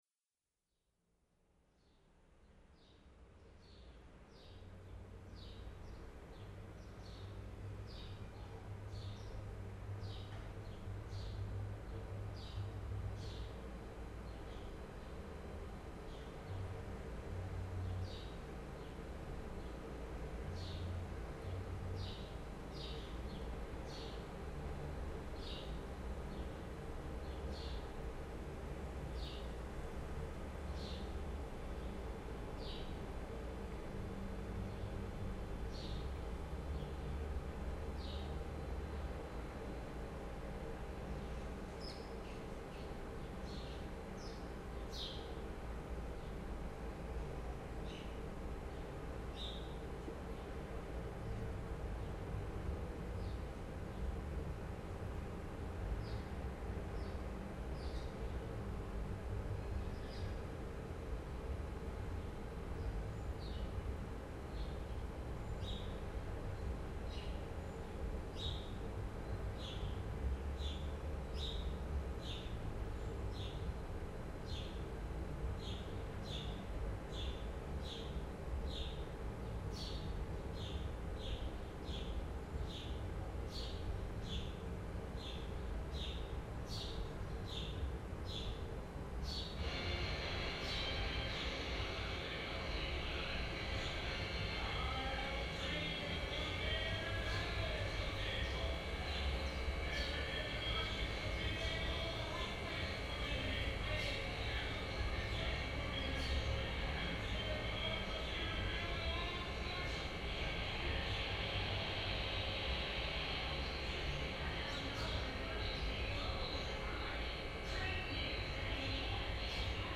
improvised and contemporary music